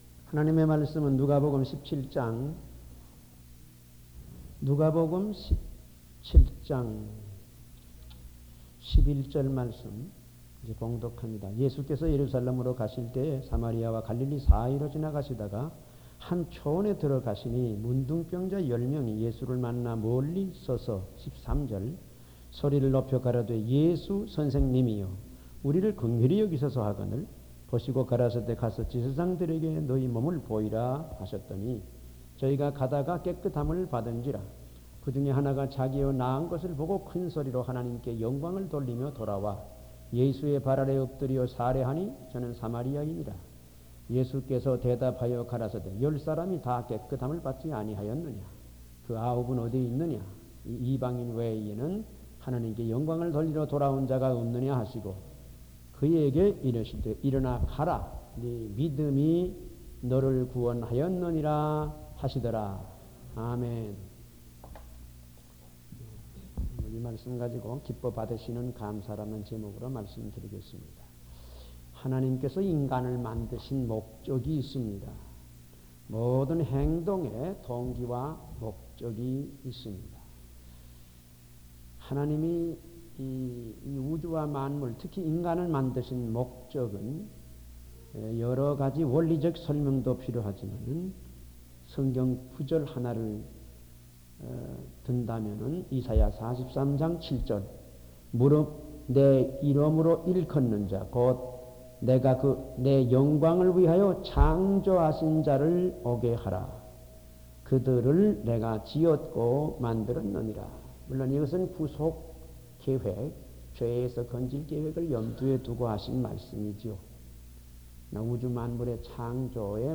Series: 주일설교